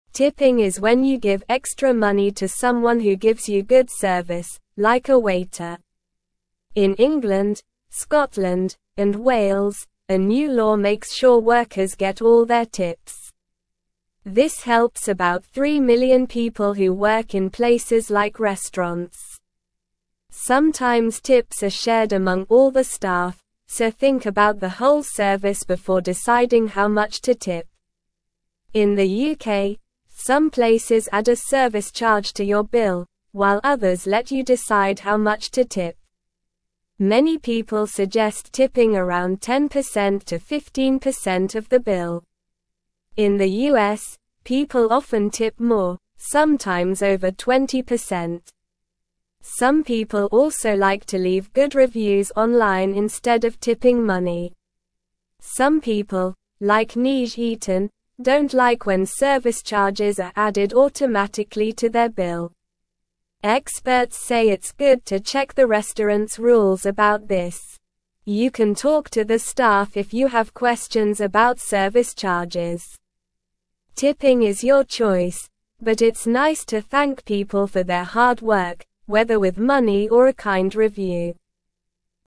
Slow
English-Newsroom-Lower-Intermediate-SLOW-Reading-Tips-and-Service-Charges-How-to-Say-Thanks.mp3